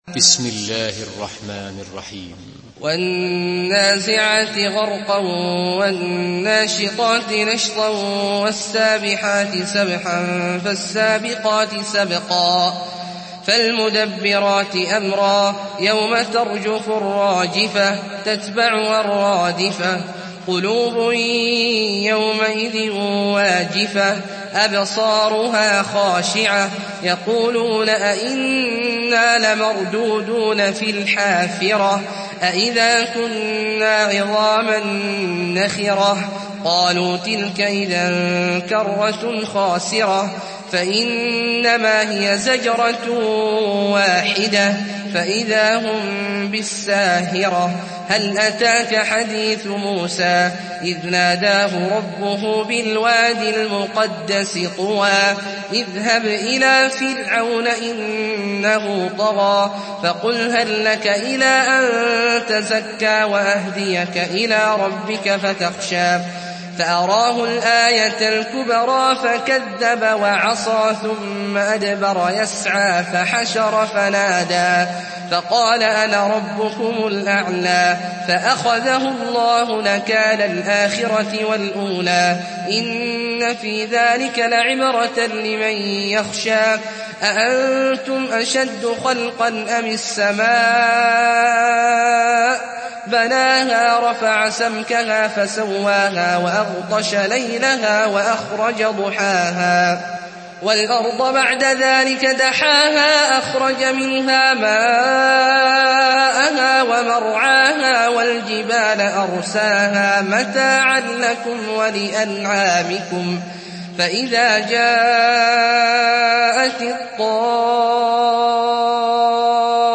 سورة النازعات MP3 بصوت عبد الله الجهني برواية حفص
مرتل